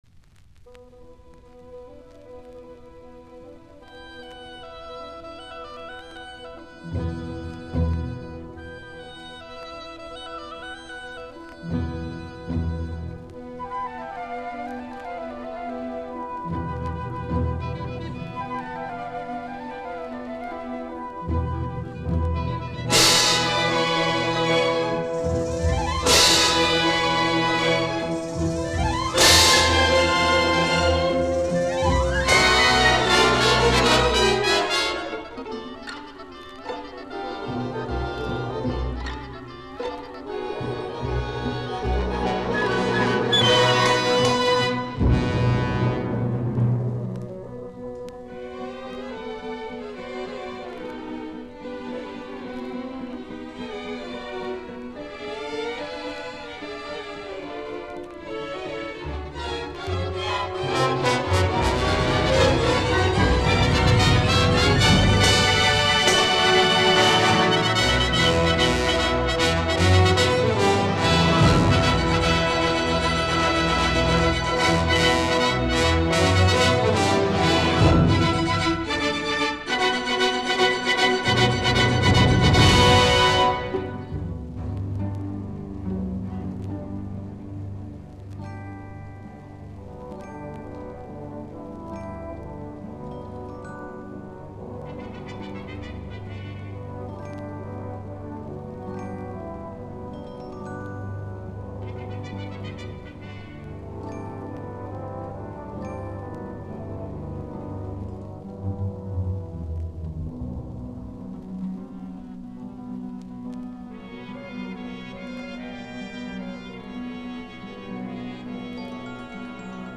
Soitinnus: Ork.
Äänitetty: 28.10.1954, Rochester, Eastman Theater.